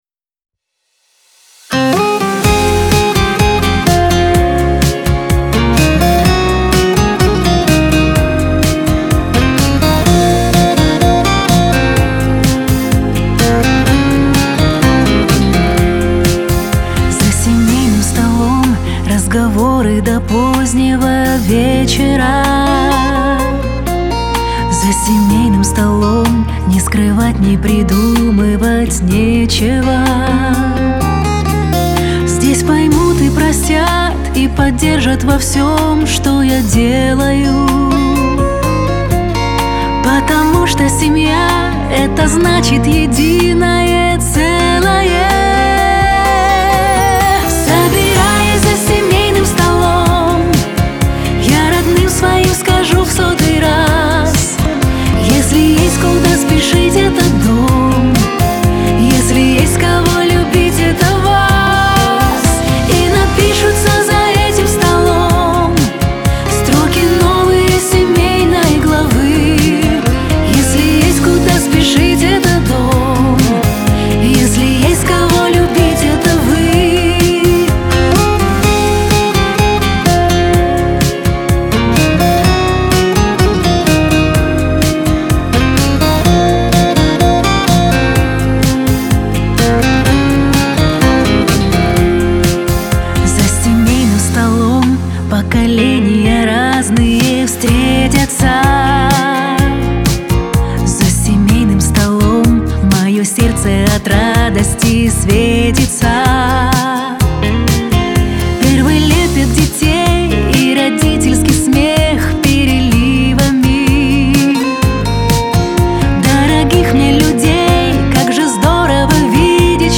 Лирика , Шансон
диско